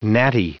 Prononciation du mot gnatty en anglais (fichier audio)
Prononciation du mot : gnatty